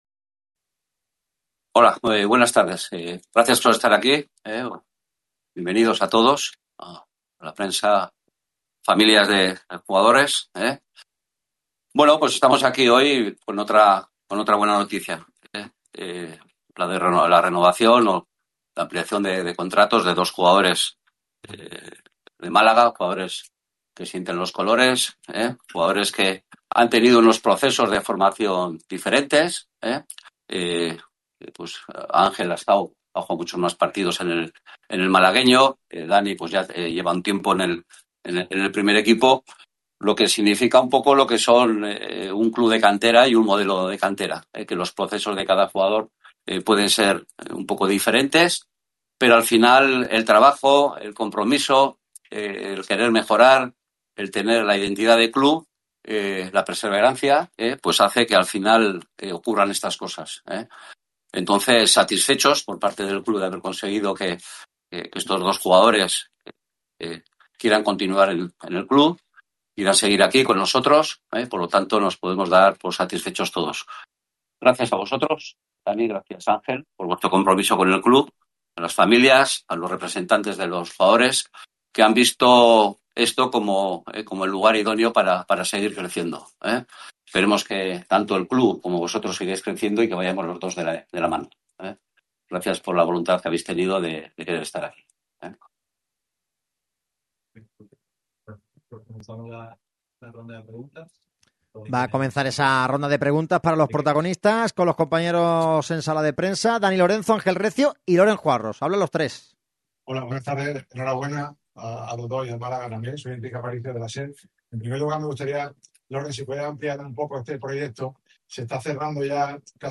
Dia emotivo en la sala de prensa de La Rosaleda.